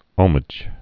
(ōmĭj)